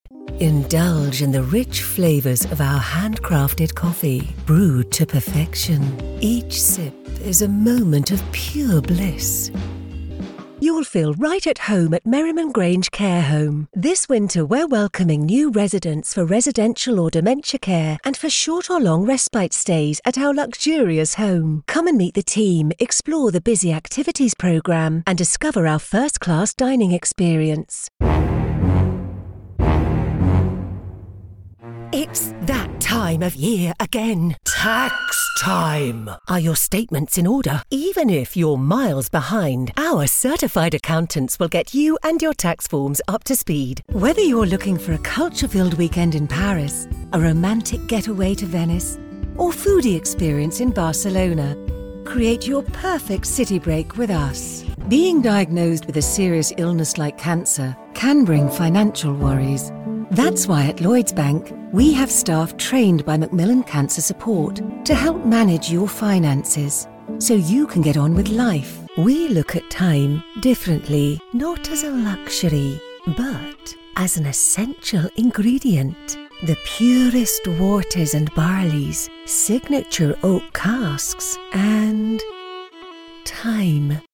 Female
British English (Native)
Assured, Authoritative, Character, Corporate, Engaging, Friendly, Gravitas, Natural, Reassuring, Smooth, Warm, Versatile
Home studio sound .mp3
Microphone: Neumann TLM 103
Audio equipment: Sound proof booth Scarlett 2i2 interface